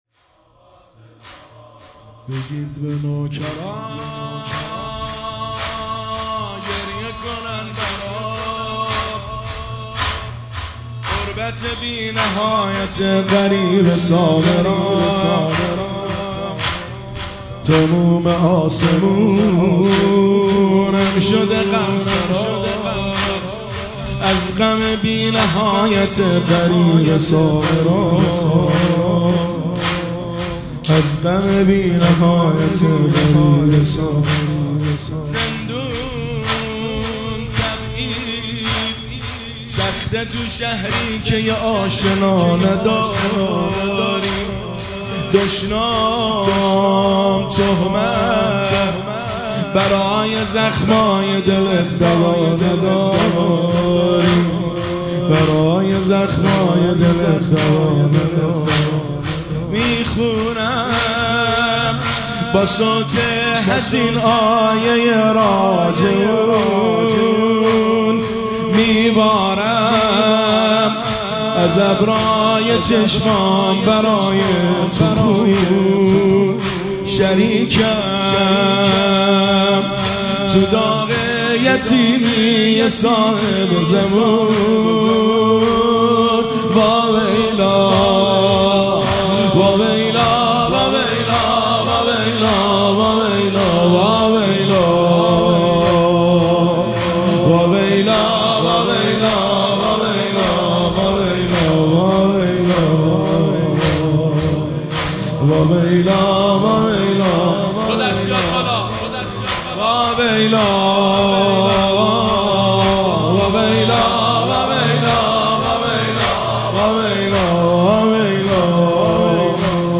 زمینه شب شهادت امام حسن عسکری (ع) 1402